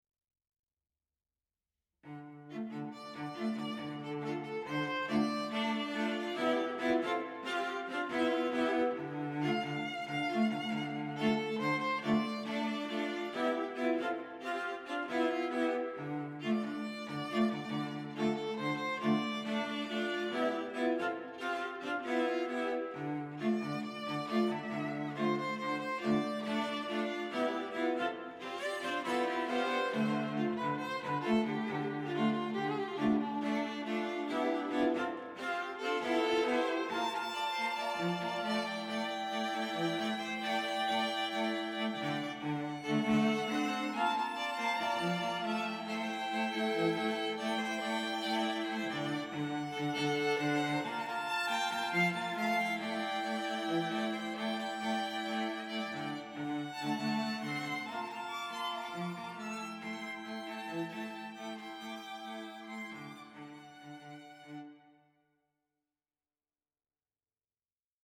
• Creative string quartet offering custom packages